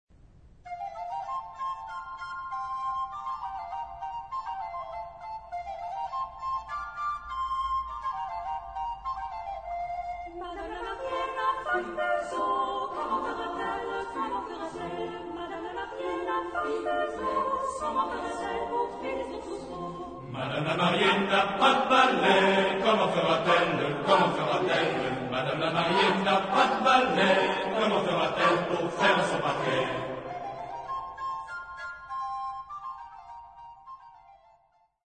Genre-Style-Form: Secular ; Popular
Mood of the piece: with feeling
Type of Choir: SATB  (4 mixed voices )
Tonality: A flat major